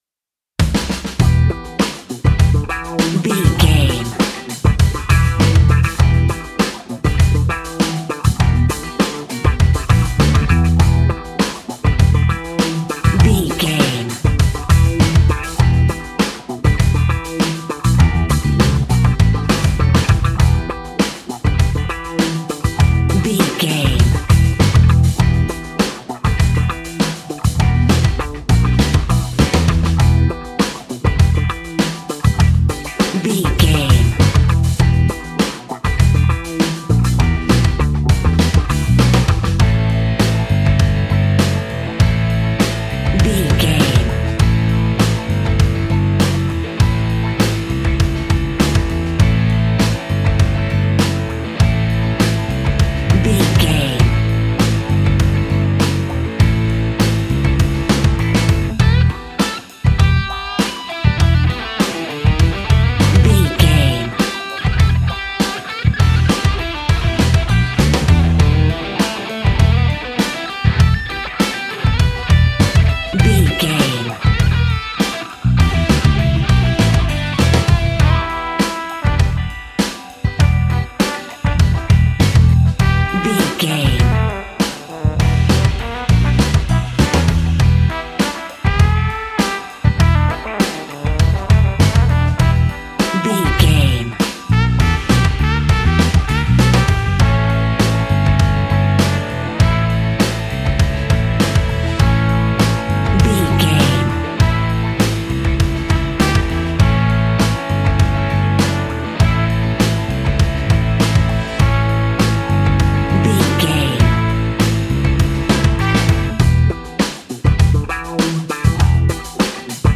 Aeolian/Minor
groovy
lively
electric guitar
electric organ
drums
bass guitar
saxophone
percussion